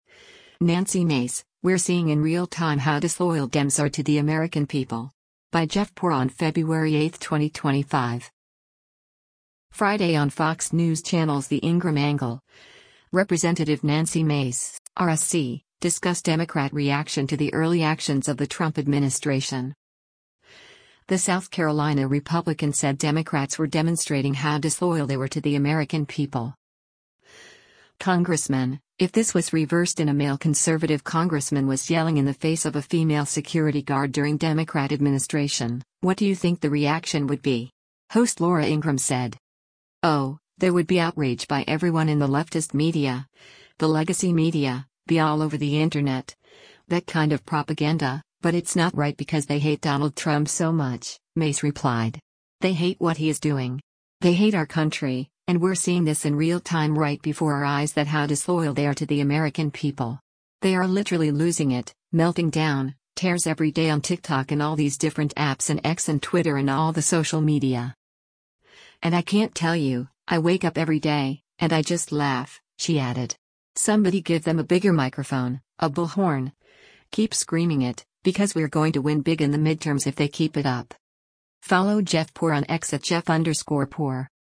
Friday on Fox News Channel’s “The Ingraham Angle,” Rep. Nancy Mace (R-SC) discussed Democrat reaction to the early actions of the Trump administration.